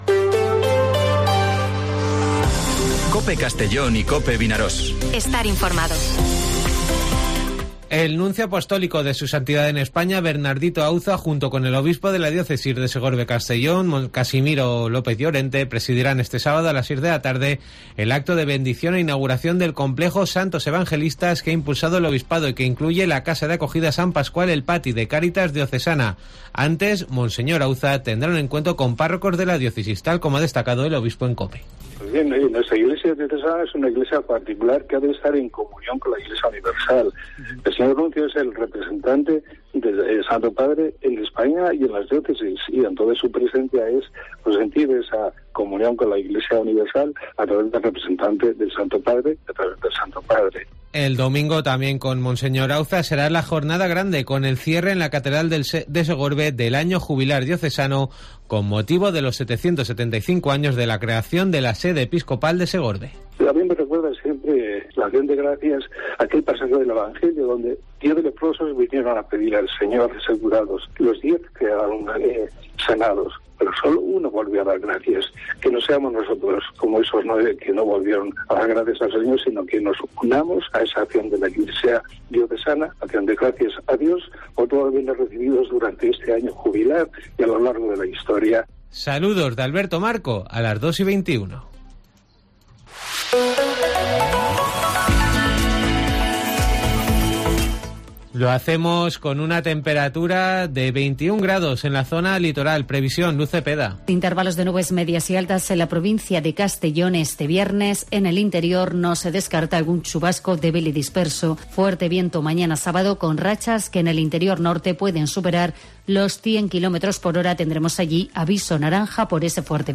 Informativo Mediodía COPE en Castellón (14/04/2023)